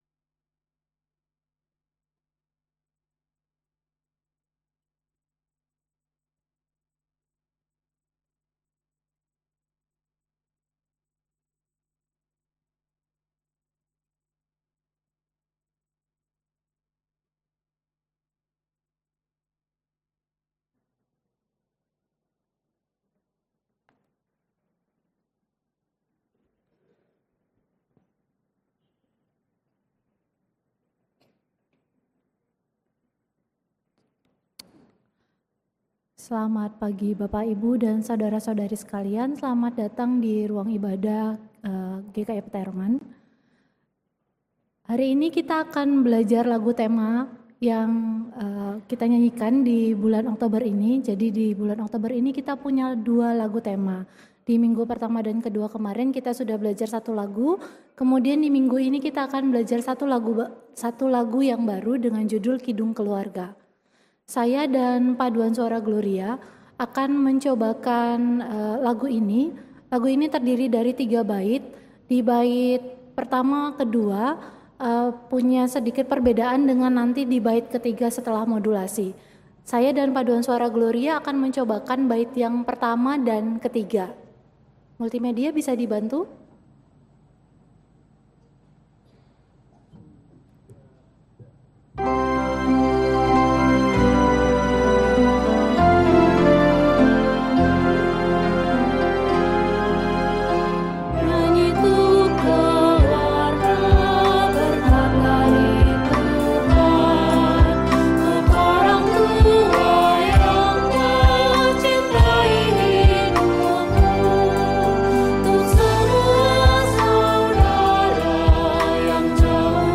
Pengkhotbah